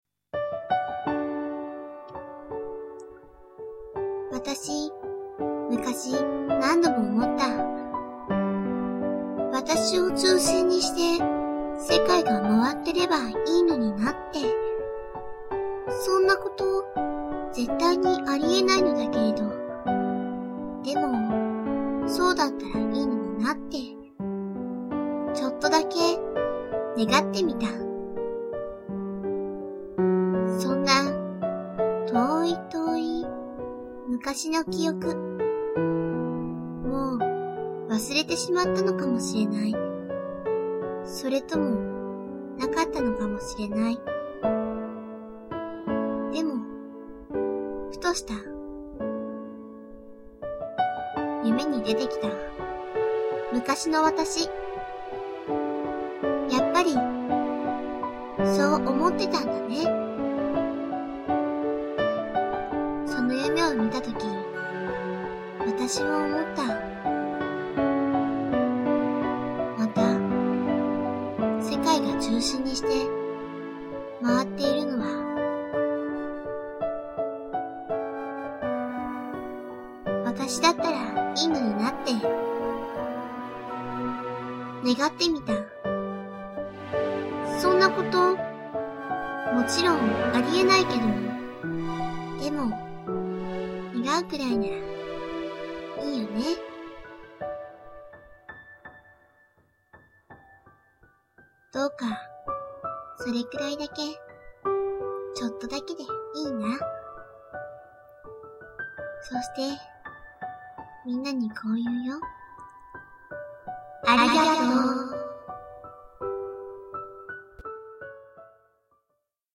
おまけ(声)